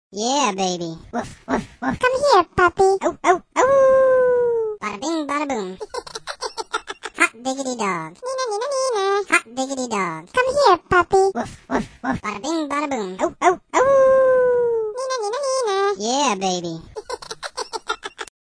It�s a little softer spoken than the larger balls and it includes the phrases "Woof! Woof!", "Owoooo", "Neener, Neener, Neener!", "Ha ha ha ha ha", "Hot Diggity Dog!", "Come here puppy!", "Yeah baby!" and "Bada-bing Bada-boom!".